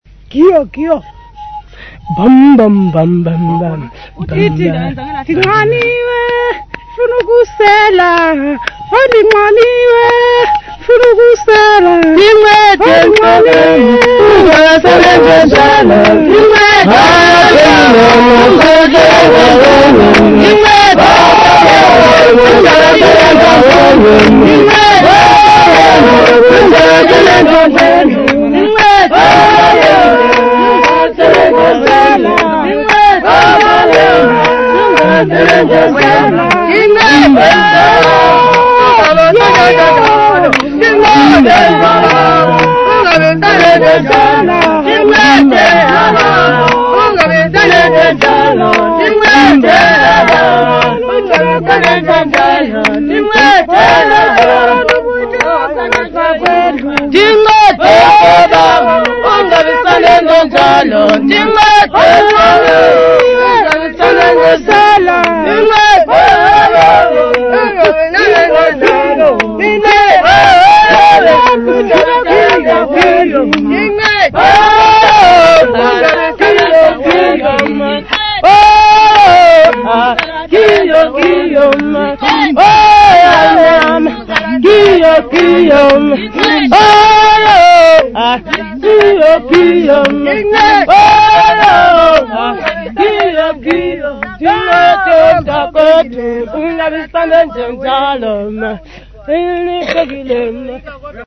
Xhosa Choir Group
Folk music
Field recordings
Traditional music, accompanied by the Uhadi bow and clapping
96000Hz 24Bit Stereo